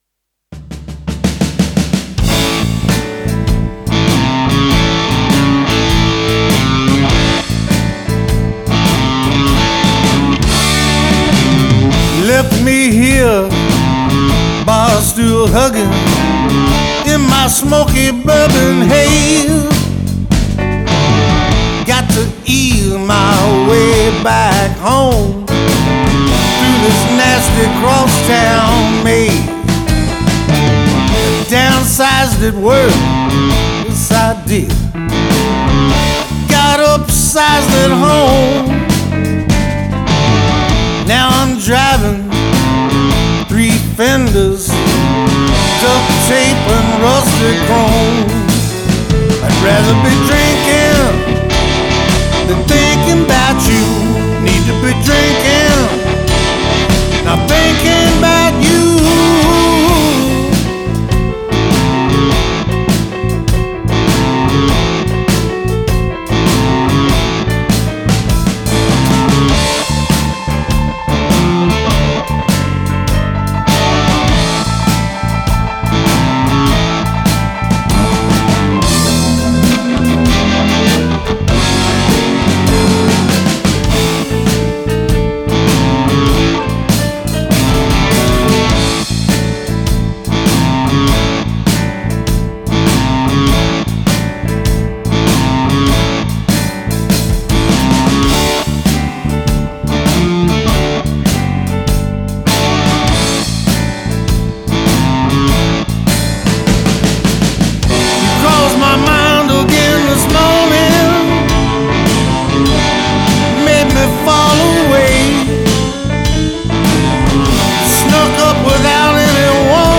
80% OTB static mix: